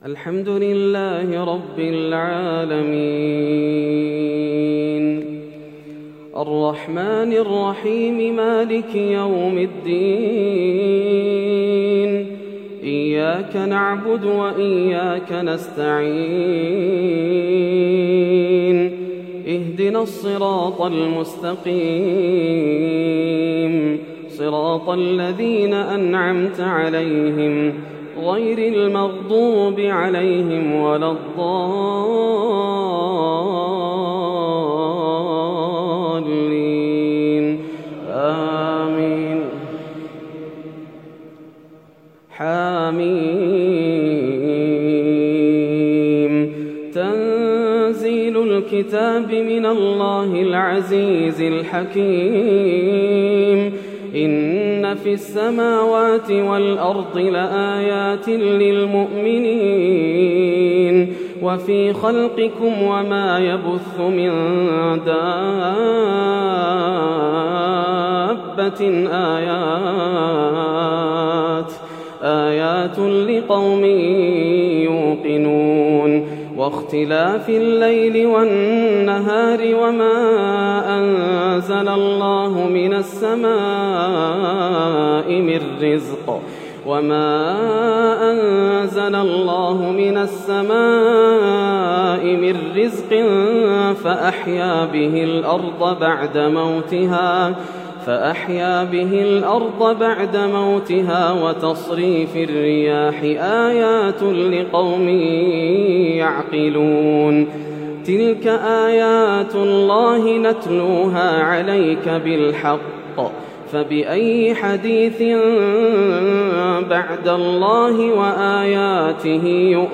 صلاة التراويح من سورة الحاثية حتى سورة الرحمن للشيخ ياسر الدوسري | ليلة ٢٧ رمضان ١٤٣١هـ > رمضان 1431هـ > مزامير الفرقان > المزيد - تلاوات الحرمين